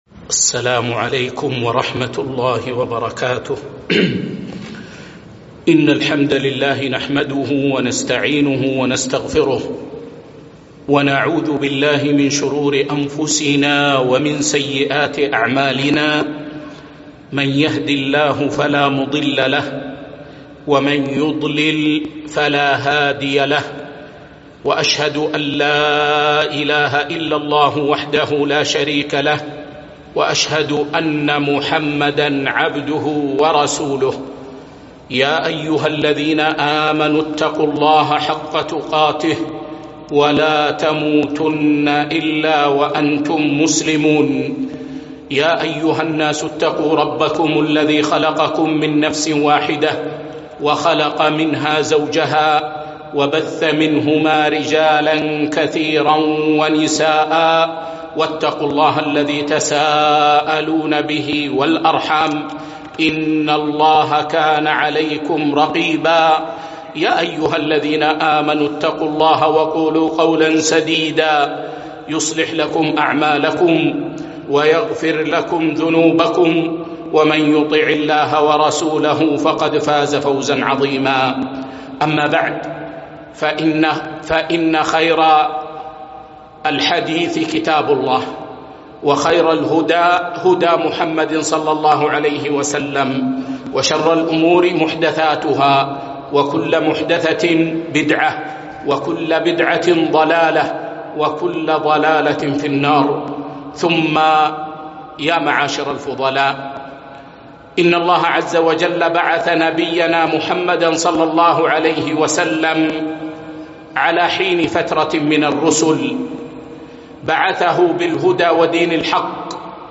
محاضرة قيمة - منهج السلف الصالح ( المفهوم - الأتباع - الآثار)